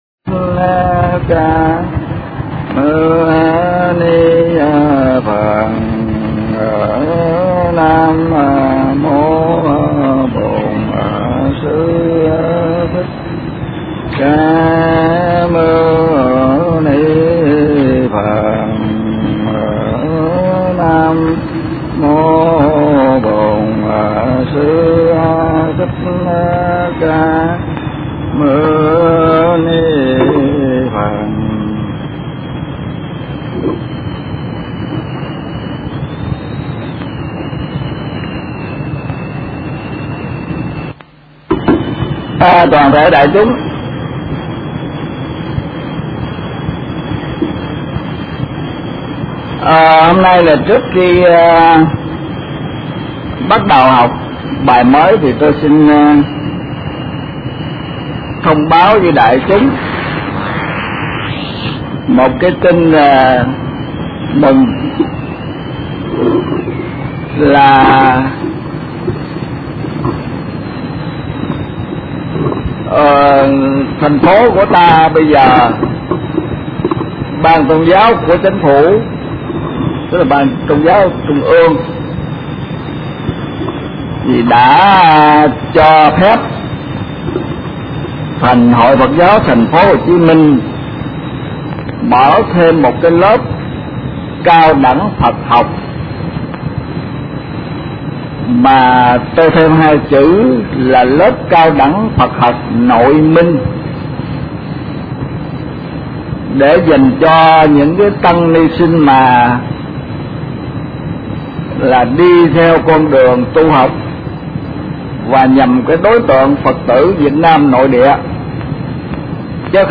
Kinh Giảng Kinh Pháp Hoa